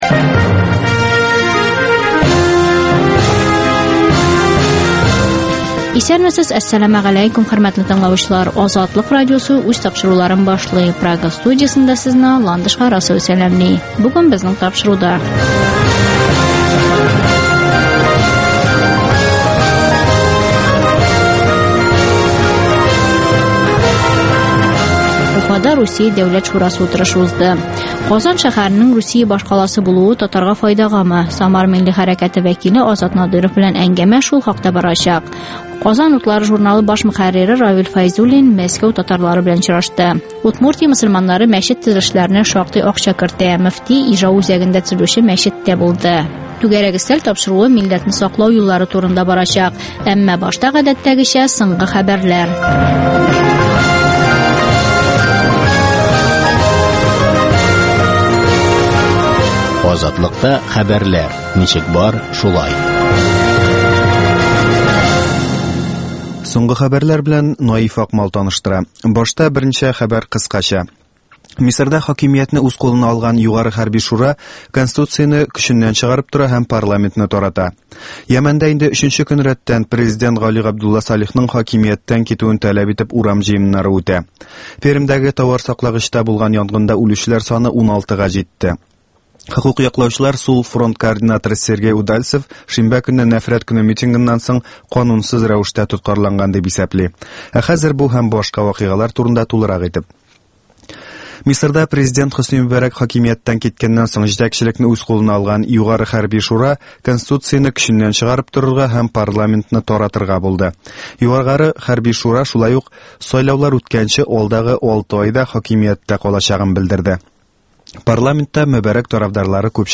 Азатлык узган атнага күз сала - соңгы хәбәрләр - башкортстаннан атналык күзәтү - татар дөньясы - түгәрәк өстәл сөйләшүе